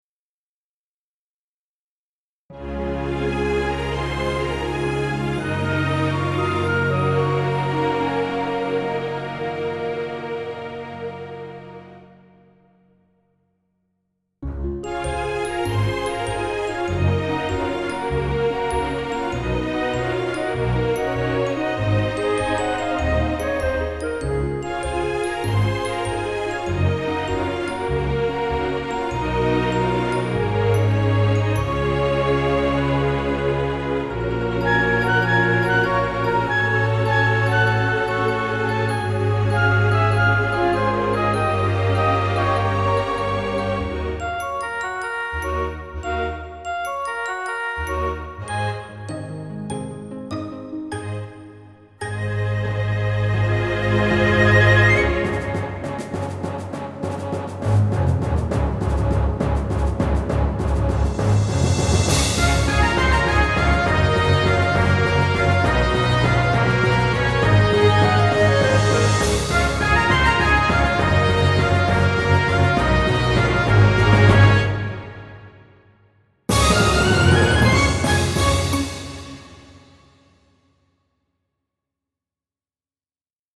オケVer